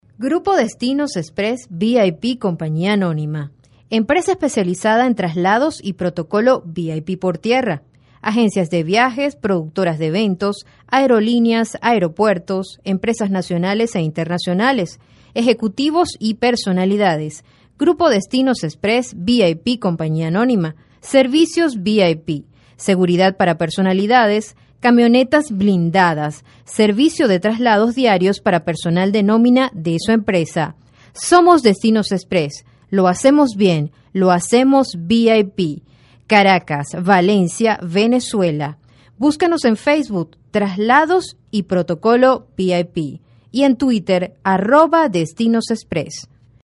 Voz joven, experiencia en grabación de comerciales, voz en off, contestadoras empresariales, programas de radio y animación en vivo
Sprechprobe: Industrie (Muttersprache):
Young voice, experience in commercial recording, voice over, business answering, radio programs and live animation